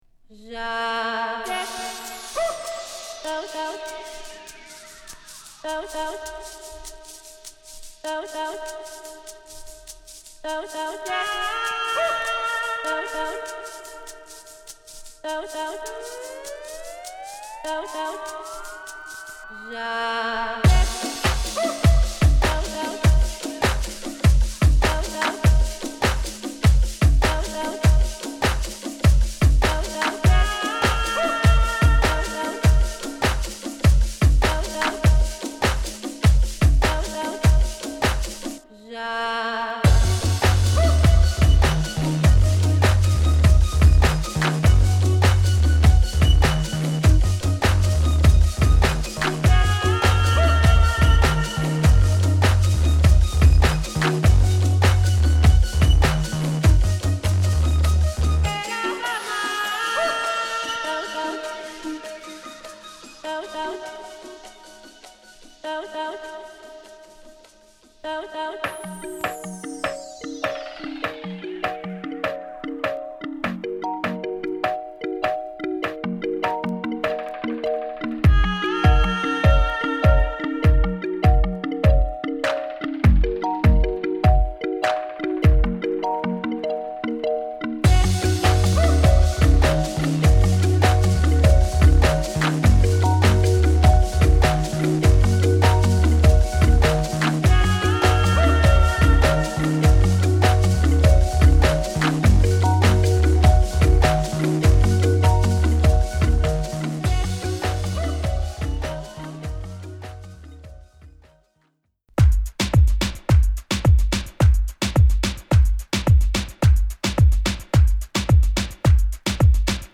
日々異様な盛り上がり見せるギリシャのBass Musicシーン。